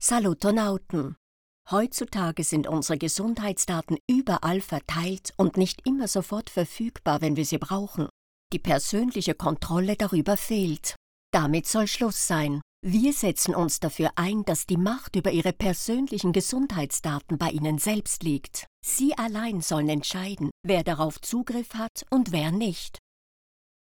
Medical Narration
RODE NT1-A microphone
Mezzo-Soprano